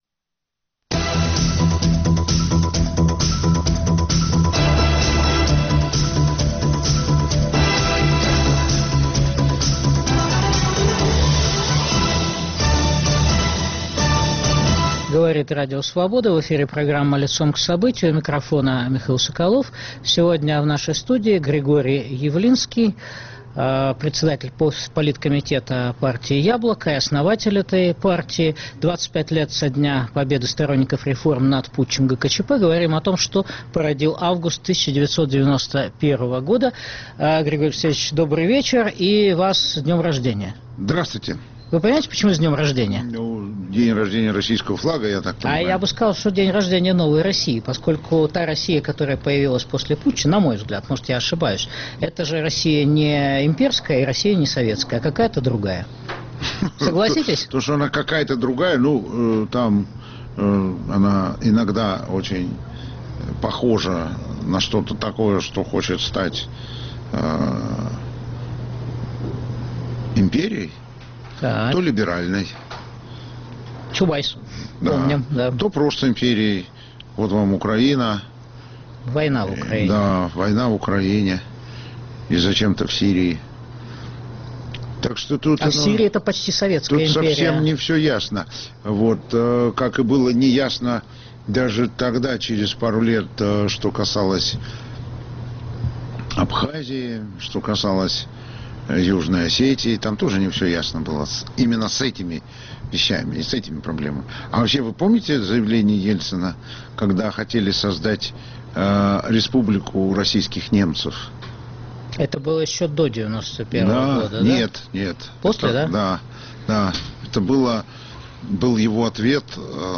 Глава Политкомитета партии "Яблоко" Григорий Явлинский в эфире Радио Свобода.